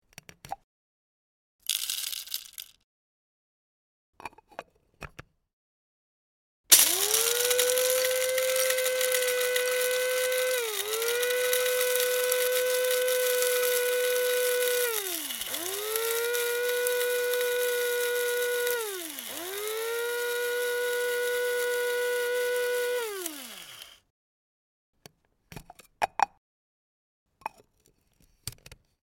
Siemens Type MC 10 coffee grinder